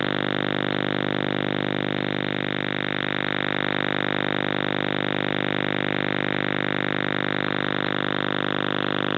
Начало » Записи » Радиоcигналы на опознание и анализ
Осталась наводка от CIS NAVY